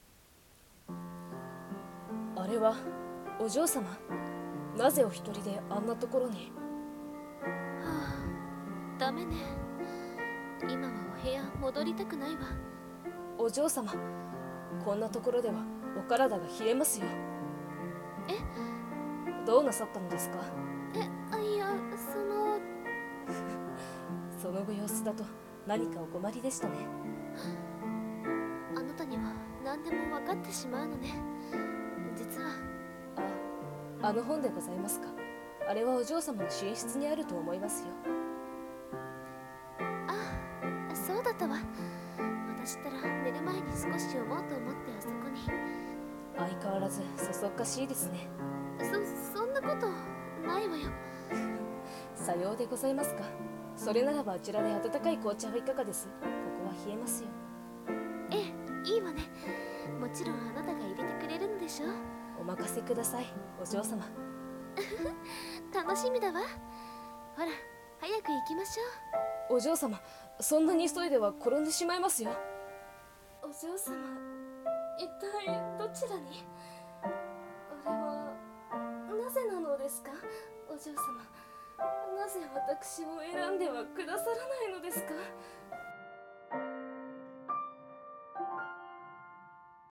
声劇 【執事とお嬢様と執事 3】